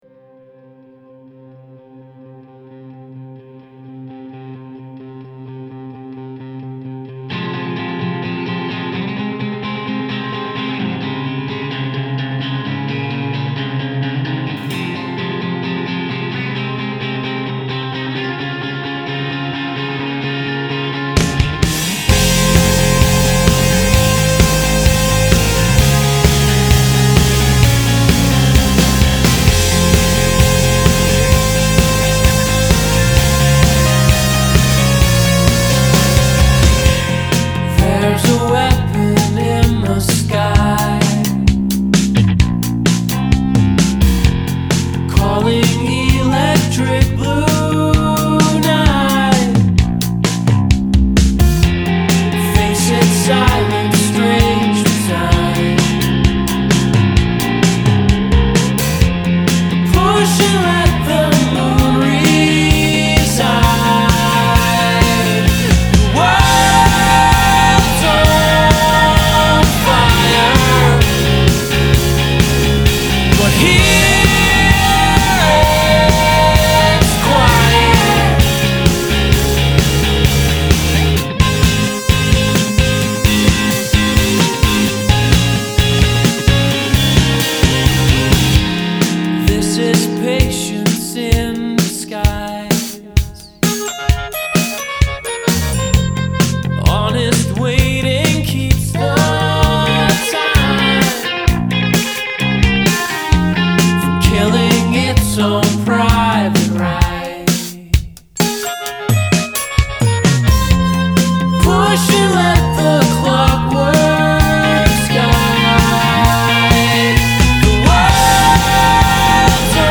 a group from Vancouver
nice, very modern indie-rock, harmony sound